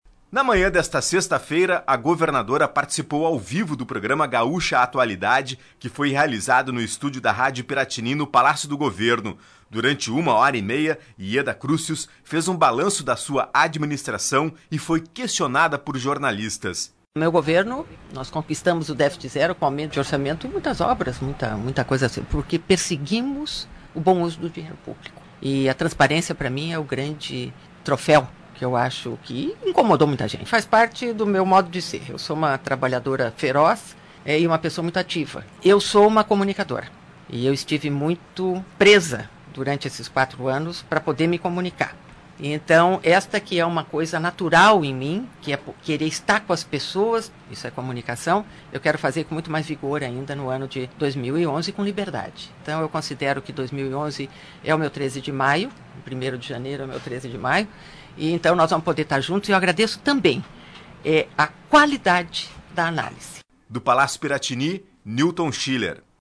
Governadora faz balanço de sua administração na Rádio Gaúcha ao vivo do Palácio Piratini